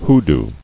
[hoo·doo]